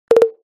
「ぽっ、ぽっ、ぽっ」という音を特徴とした楽しくて軽快な通知音です。